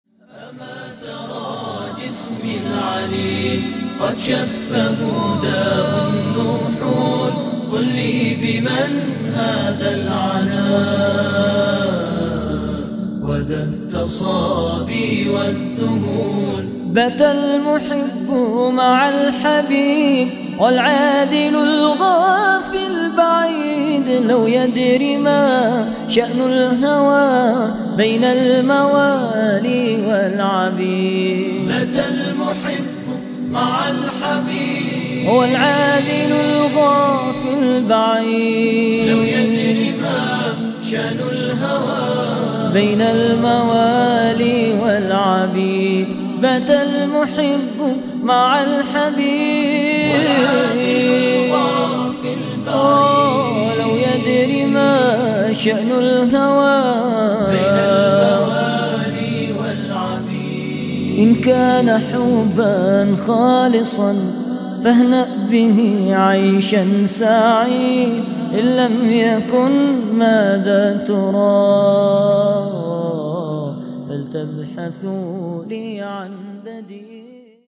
من هو هذا المنشد :
كمايبدولــي هذا المنــشد يمني ،،